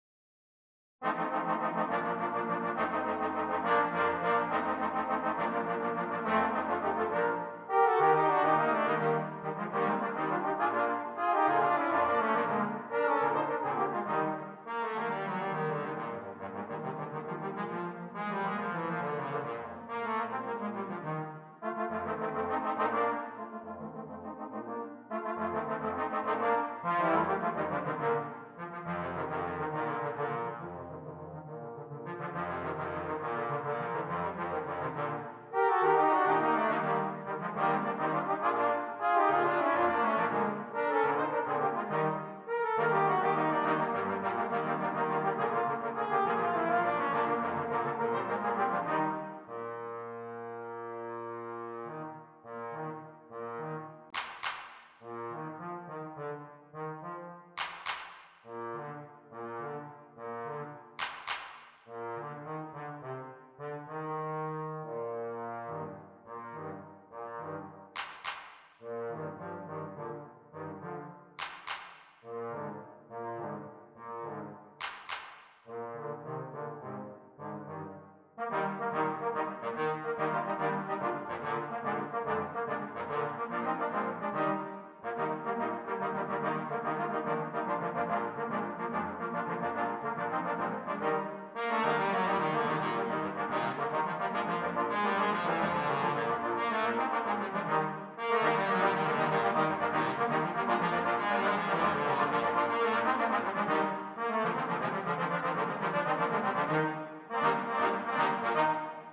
4 Trombones
Traditional